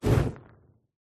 Масляная лампа ярко вспыхивает